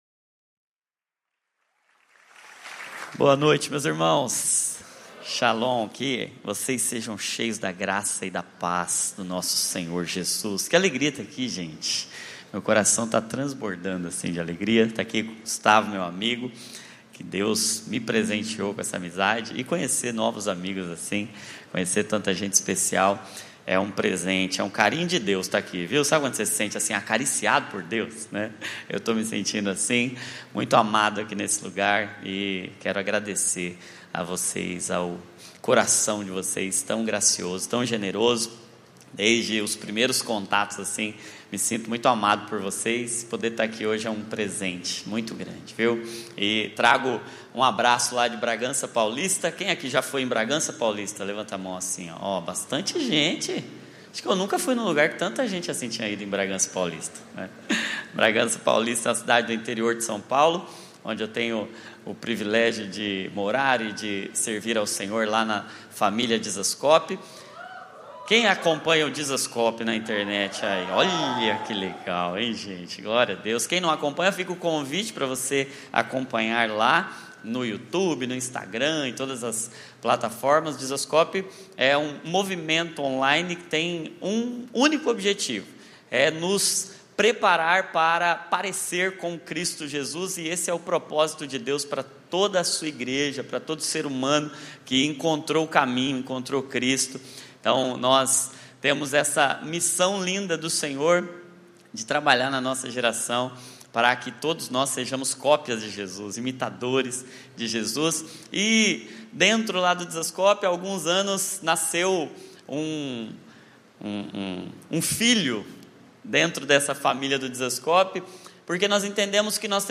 como parte da série YTH CON 25 na Igreja Batista do Recreio